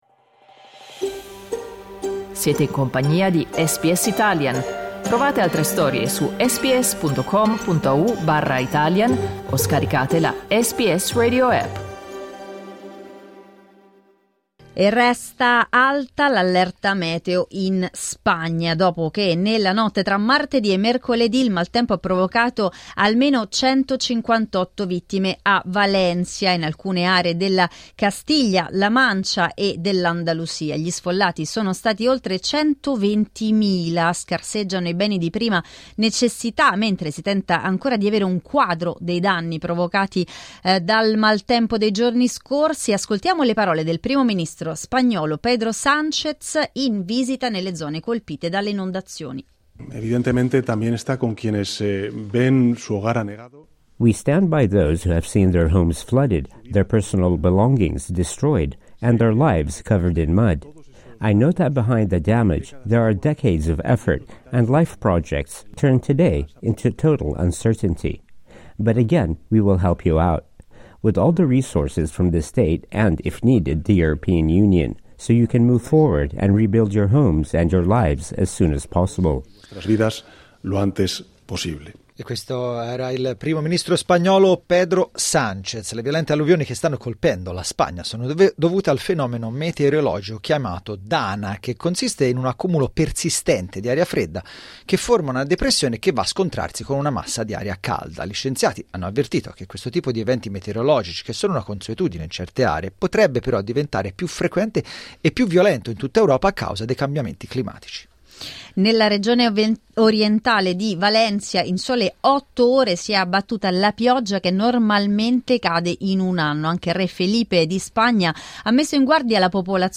Il bilancio provvisorio delle vittime dell'alluvione che ha colpito la città di Valencia e alcune aree della Castiglia-La Mancia e dell'Andalusia è salito a 158. Ne abbiamo parlato con alcuni italiani residenti nella zona.